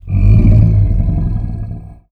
MONSTERS_CREATURES
MONSTER_Growl_Medium_20_mono.wav